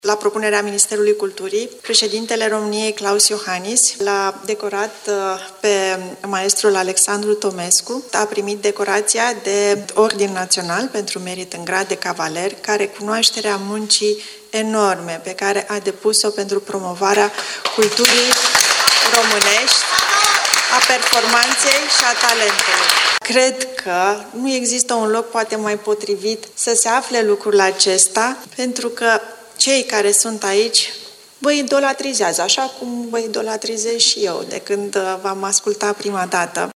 Anunțul a fost făcut, la Timișoara, de ministrul Culturii, Raluca Turcan, care a participat la decernarea premiilor din cadrul Concursului Remember Enescu 2023: